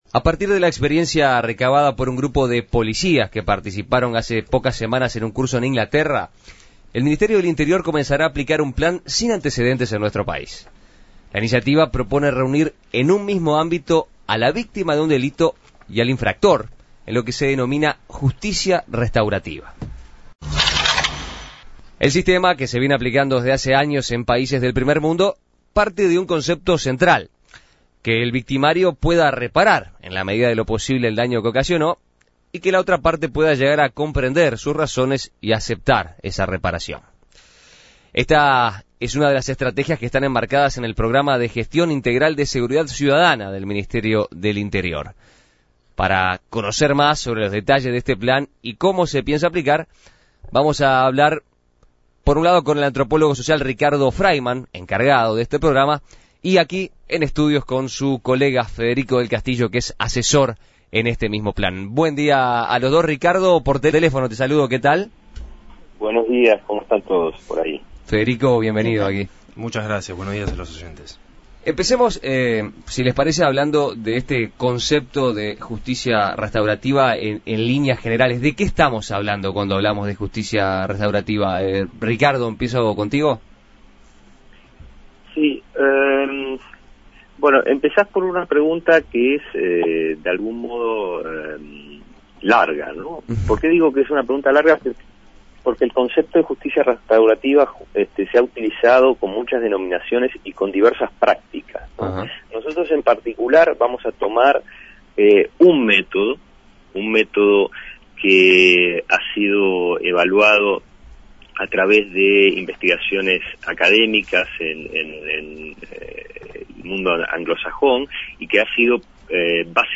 En Perspectiva dialogó con los antropólogos